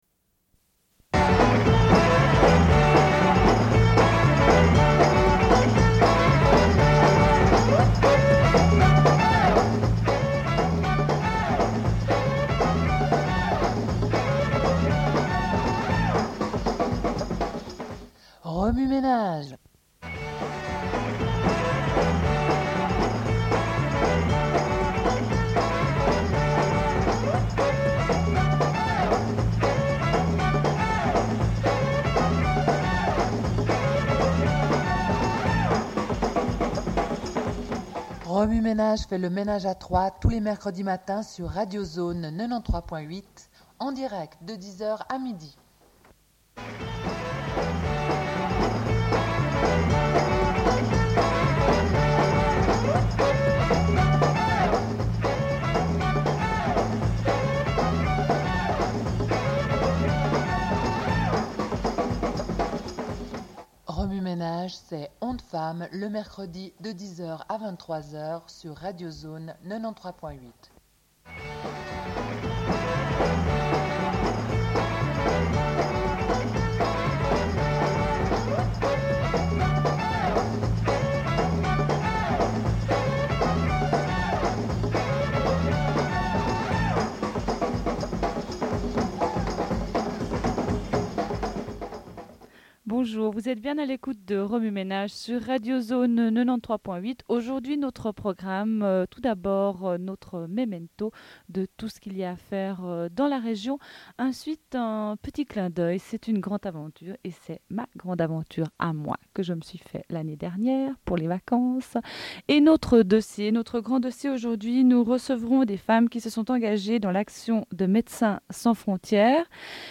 Une cassette audio, face A00:31:47